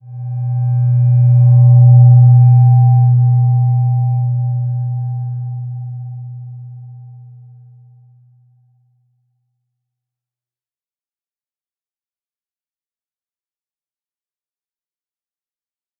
Slow-Distant-Chime-B2-f.wav